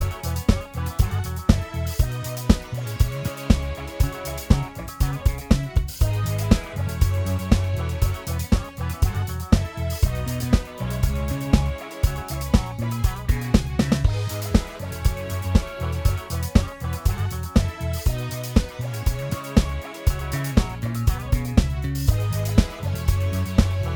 Minus Main Guitar Dance 4:10 Buy £1.50